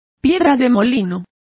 Complete with pronunciation of the translation of millstone.